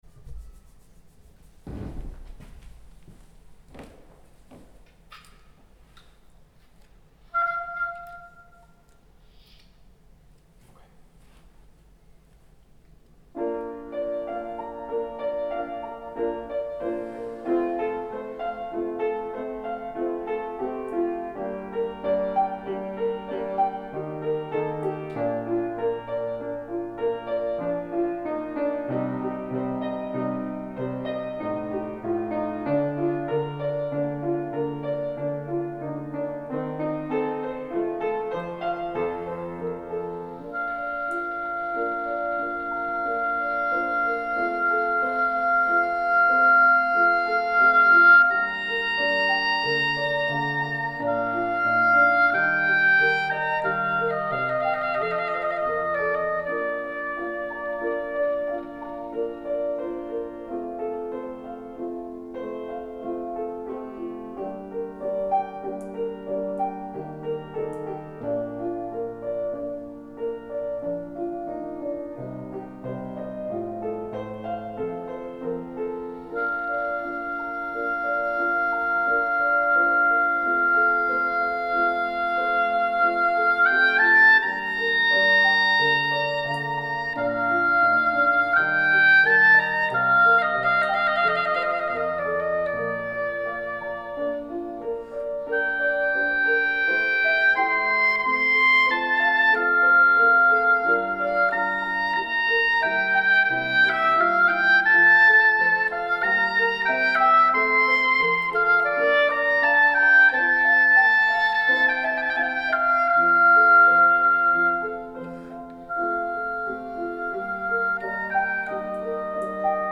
Oboe
piano